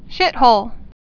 (shĭthōl)